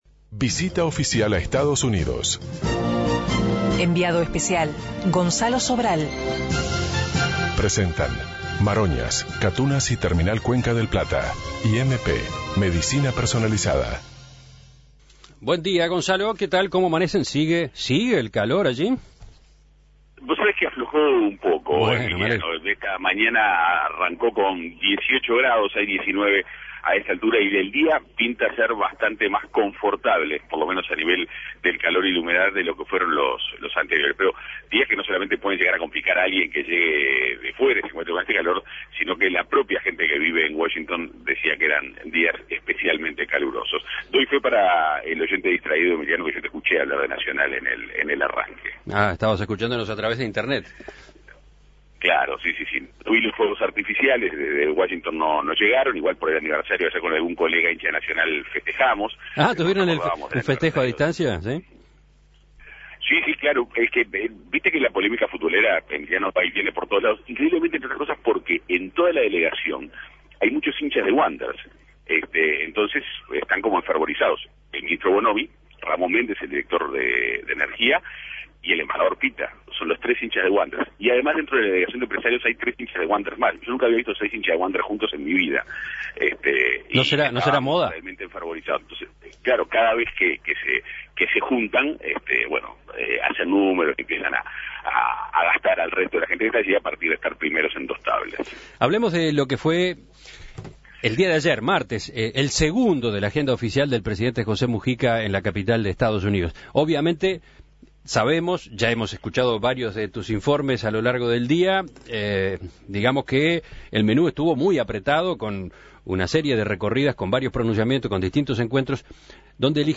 Gira presidencial